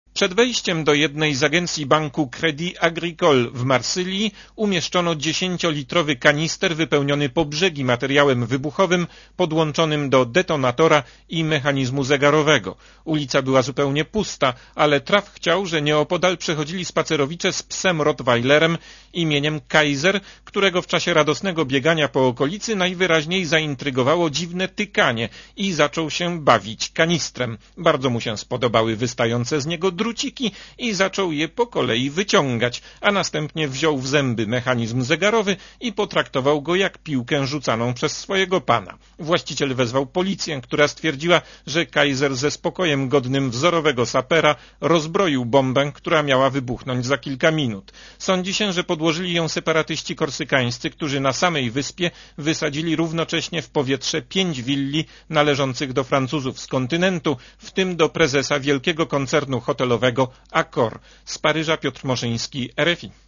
Posłuchaj relacji paryskiego korespondenta Radia Zet (247 KB)